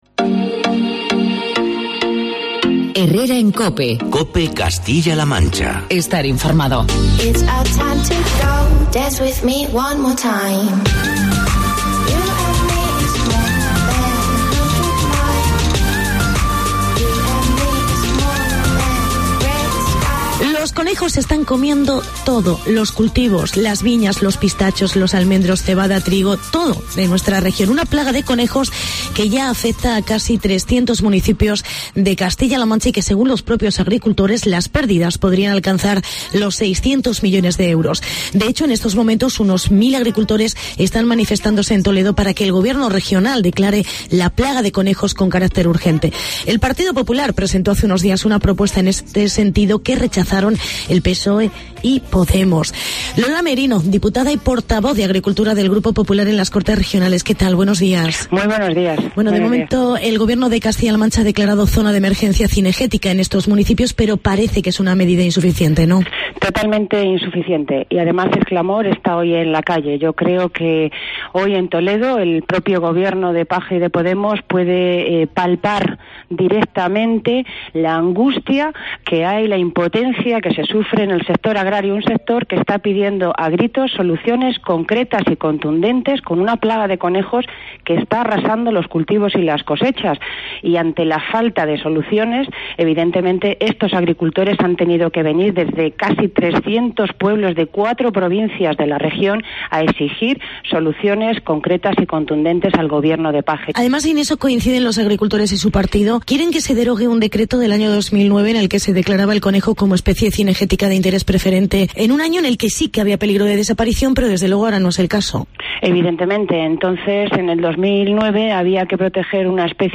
Entrevista con la diputada Lola Merino